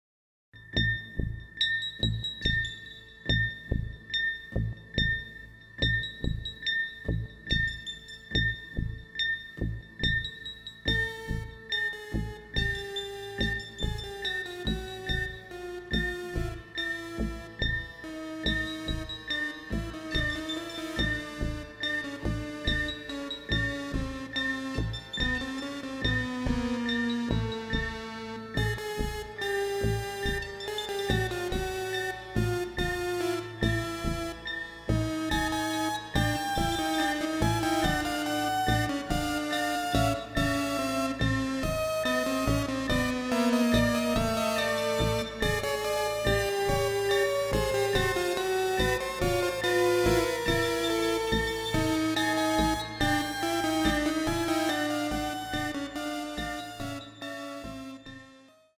additional electronic music
newly recorded renderings